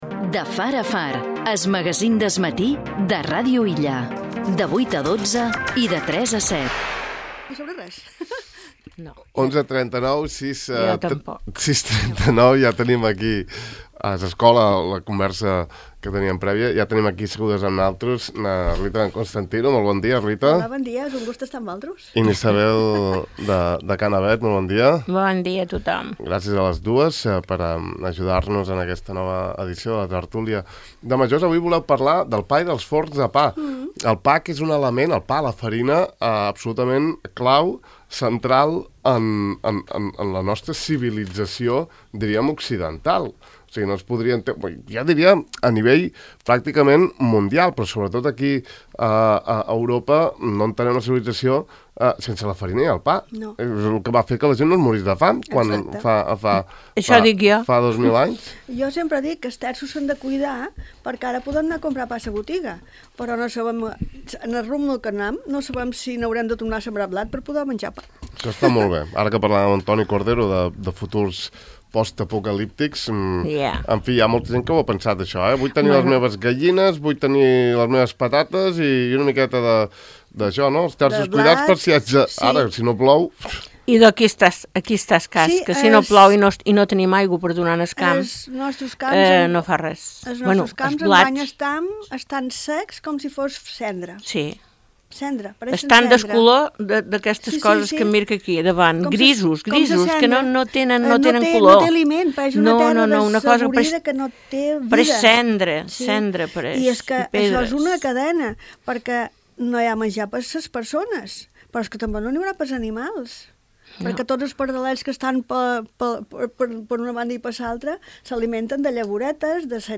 El pa en la tertúlia de majors